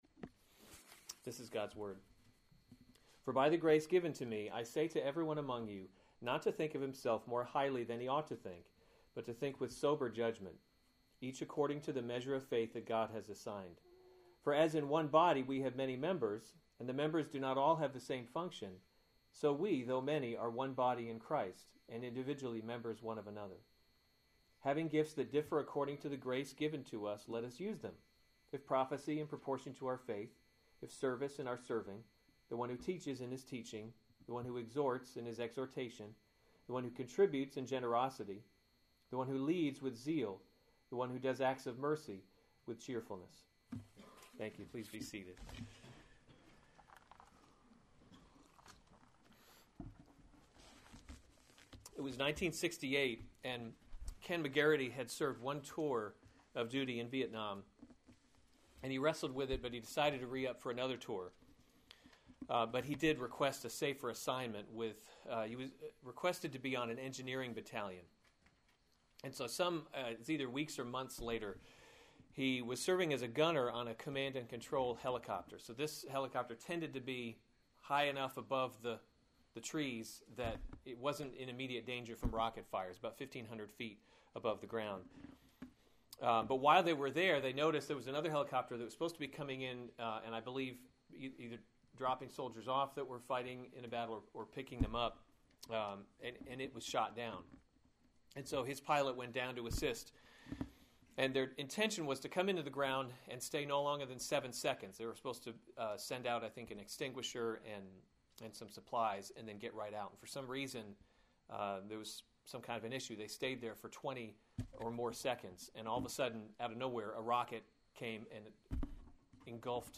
February 21, 2015 Romans – God’s Glory in Salvation series Weekly Sunday Service Save/Download this sermon Romans 12:3-8 Other sermons from Romans Gifts of Grace 3 For by the grace given […]